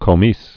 (kō-mēs, kə-)